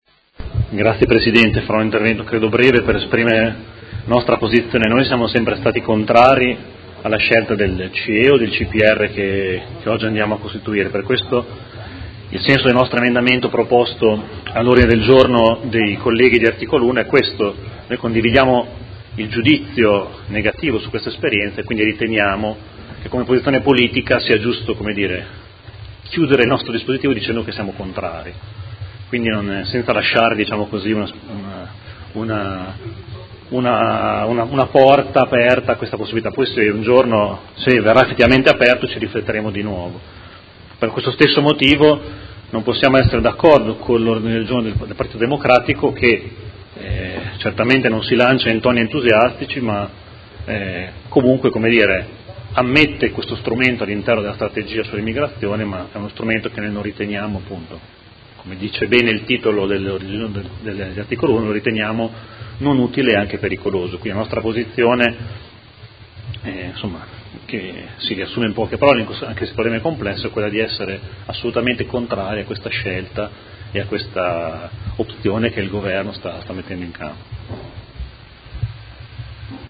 Seduta del 12/04/2018 Dibattito. Ordine del giorno 54394, Emendamento 54490 e ordine del giorno 54480 sulla sicurezza.